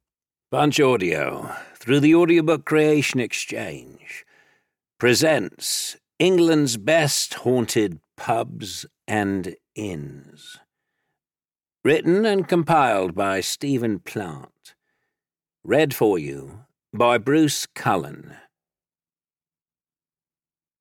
England's Haunted Pubs (EN) audiokniha
Ukázka z knihy